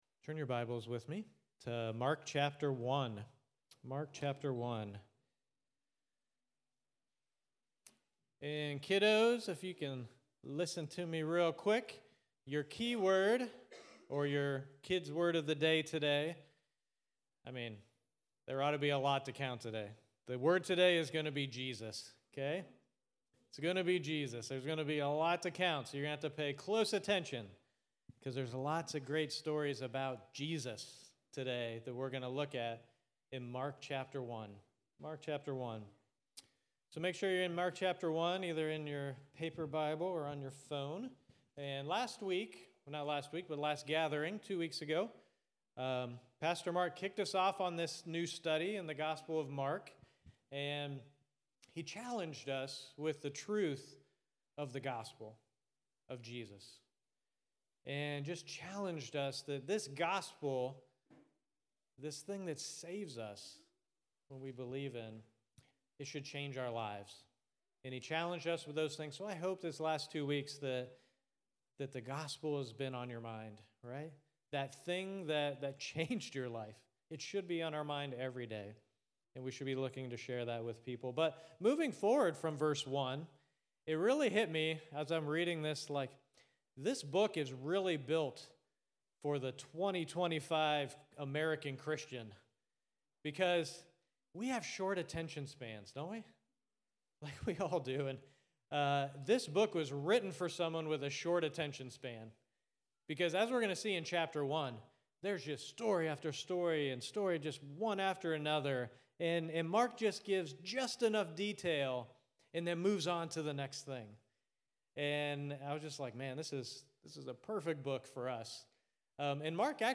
at Cincy Gathering